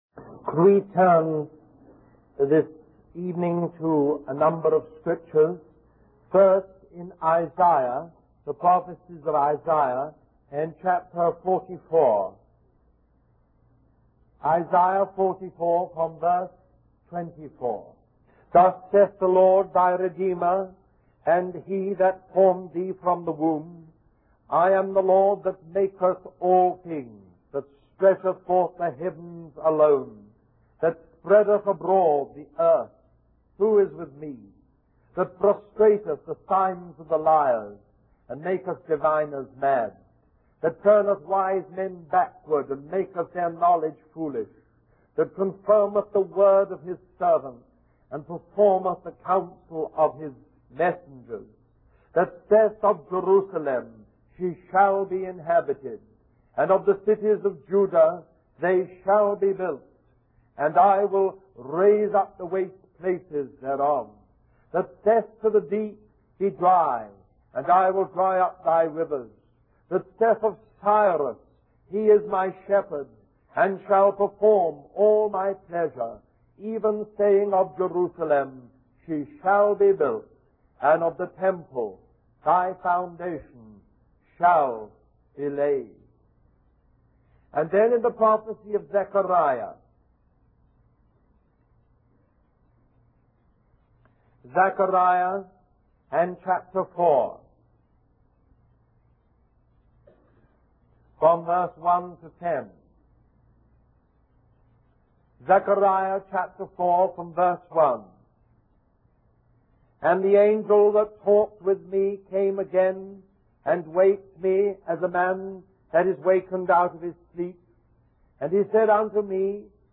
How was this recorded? Christian Family Conference